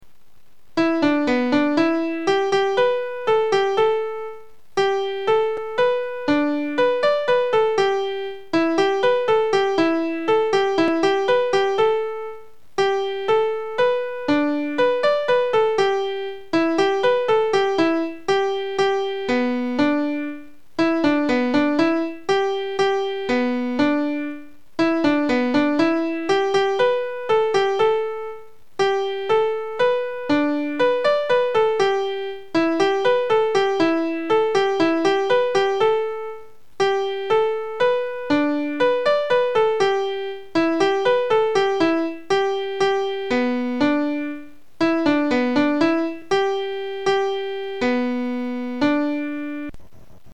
Nhạc Cổ Truyền